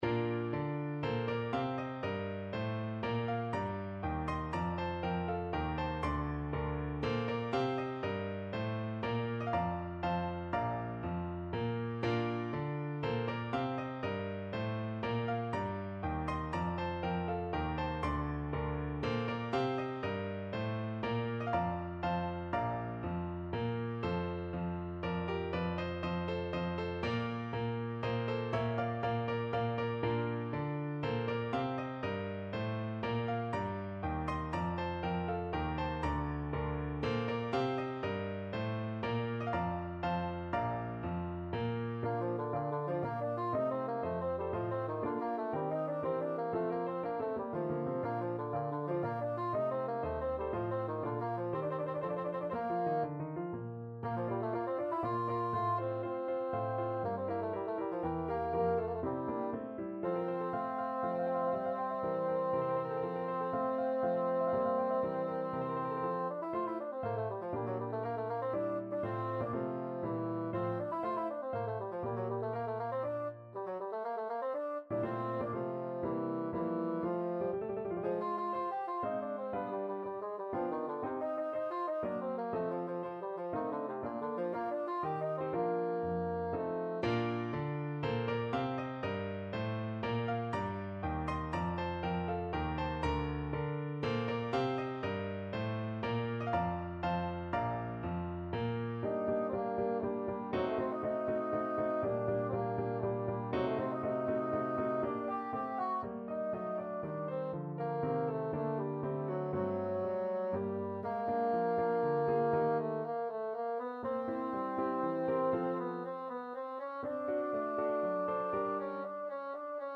Bassoon
Bb major (Sounding Pitch) (View more Bb major Music for Bassoon )
3/4 (View more 3/4 Music)
=120 RONDO Tempo di Menuetto
Classical (View more Classical Bassoon Music)